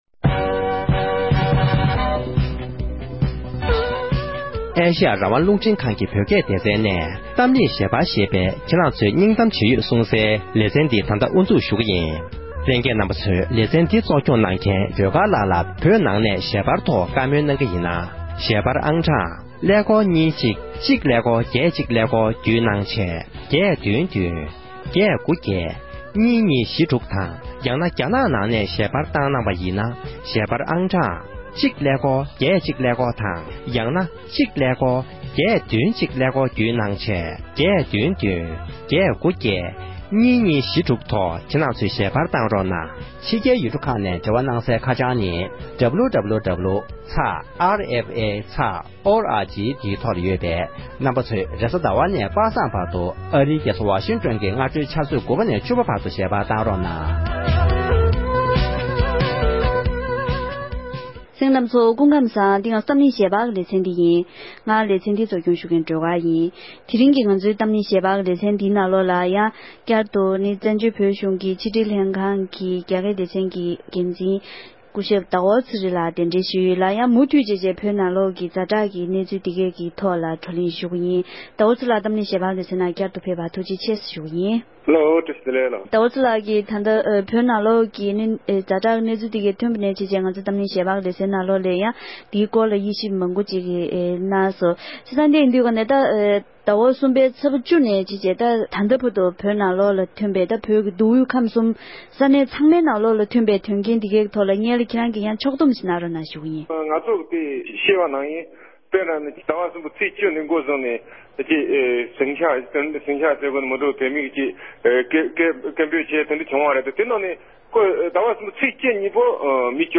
དེ་རིང་གི་གཏམ་གླེང་ཞལ་པར་གྱི་ལེ་ཚན་ནང༌།